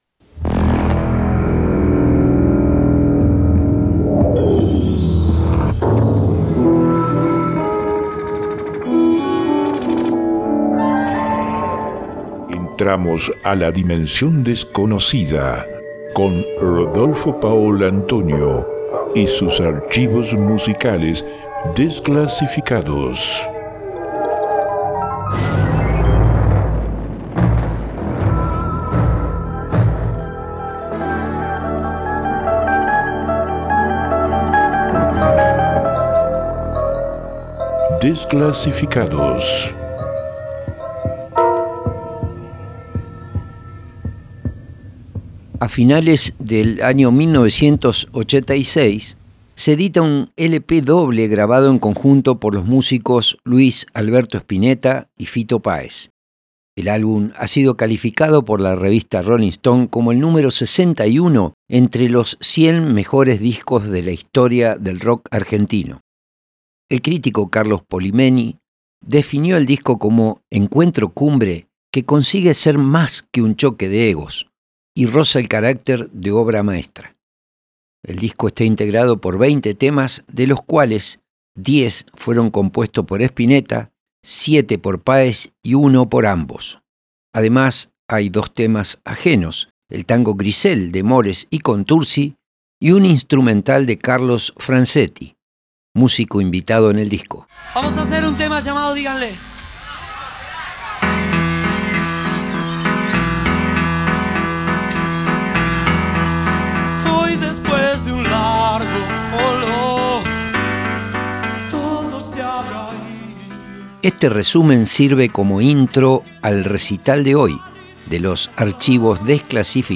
en Santa Fe el 15/9/1986 y en Rosario el 21/9/1986